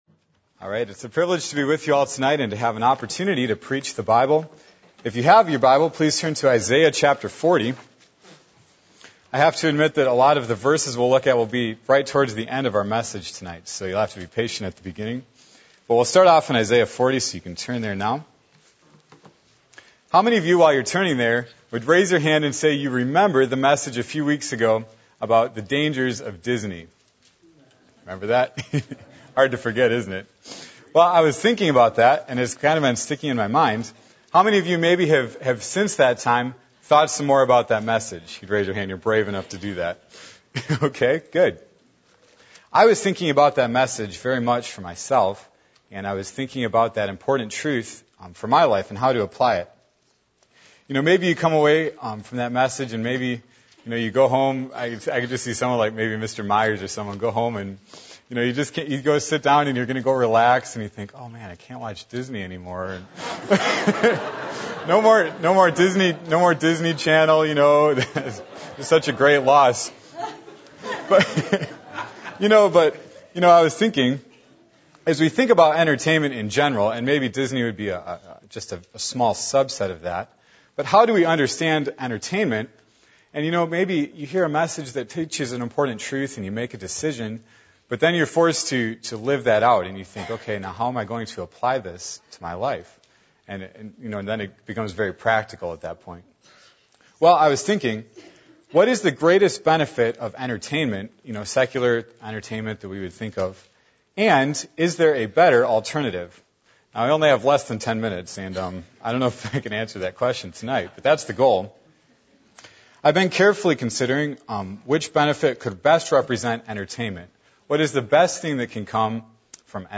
Watch Night Service: Young Preachers